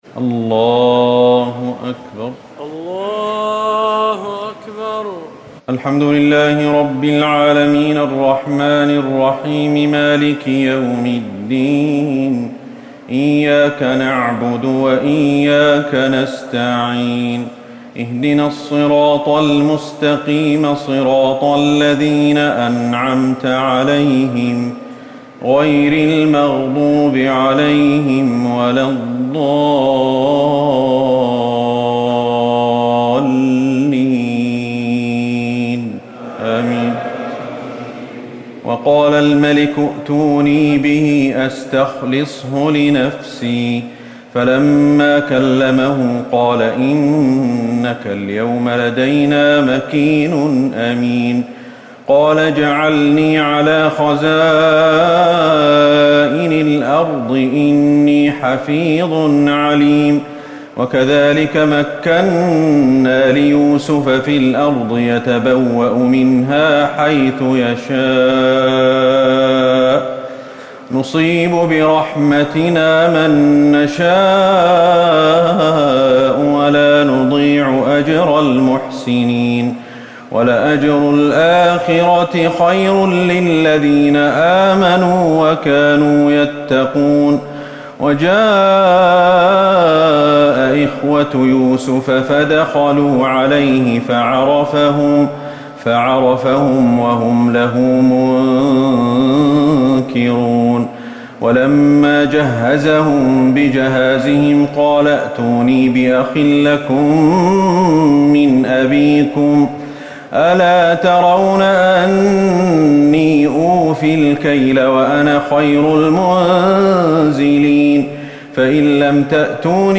ليلة ١٢ رمضان ١٤٤٠ من سورة يوسف ٥٣ - الرعد ١٨ > رمضان 1440هـ > التراويح